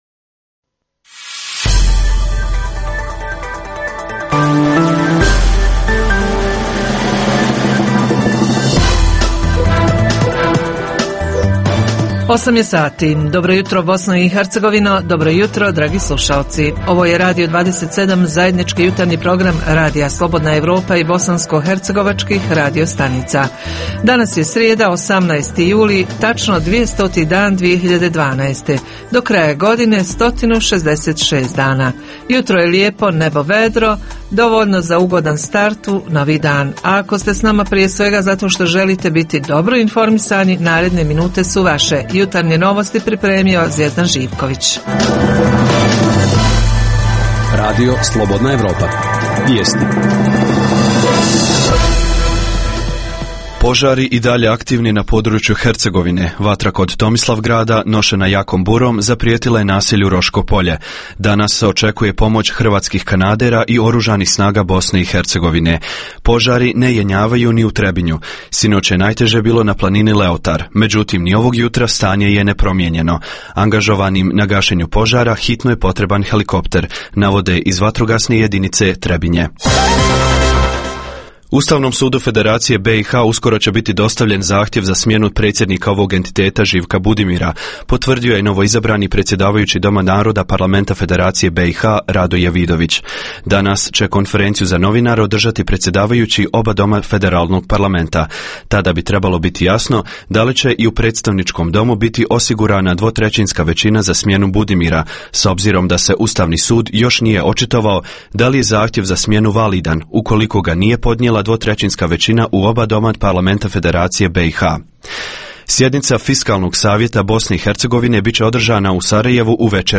Javljanje reportera iz Bijeljine, povodom velike štete koju je suša nanijela povrću a posebno voću – čak 90 posto stabala šljive ozbiljno ugroženo.